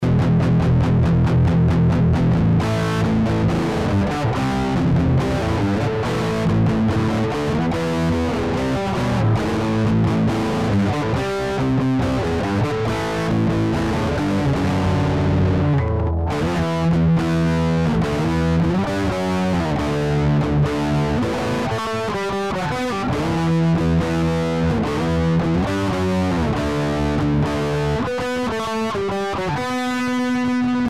Вот недавно сделал небольшой модерн для своего маршала: удалил из цепи конденсатор С22!